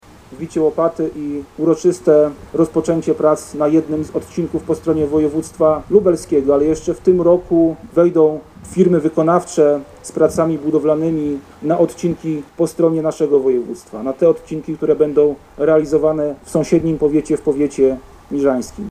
Symbolicznym wbiciem łopaty w Łążku Ordynackim zainaugurowano w poniedziałek budowę kolejnego odcinka drogi ekspresowej S19. To fragment od węzła Janów Lubelski Południe do węzła Lasy Janowskie na granicy województw lubelskiego i podkarpackiego. Mówił o tym wiceminister infrastruktury Rafał Weber.